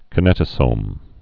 (kə-nĕtə-sōm, -nētə-, kī-)